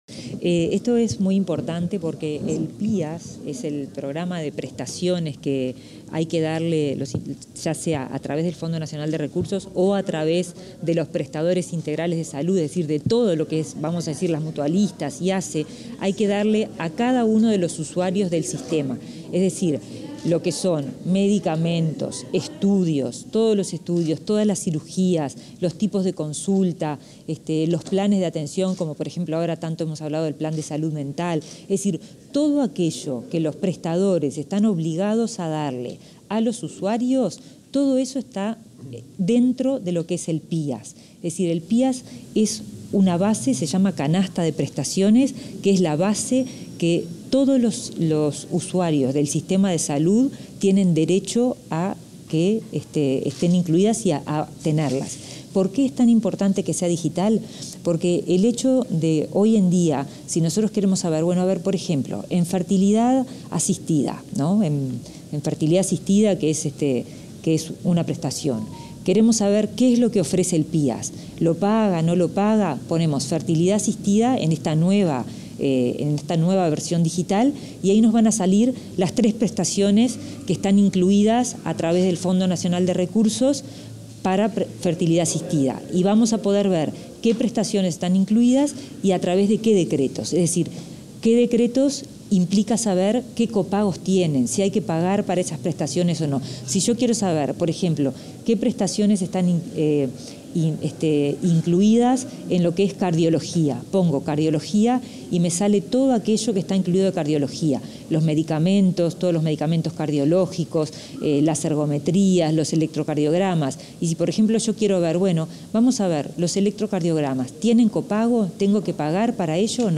Declaraciones de la ministra de Salud Pública, Karina Rando
Tras el lanzamiento del Plan Integral de Atención en Salud, este 17 de junio, la ministra de Salud Pública, Karina Rando, realizó declaraciones a la